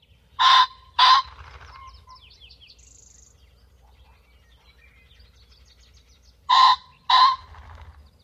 雉鸡鸣叫声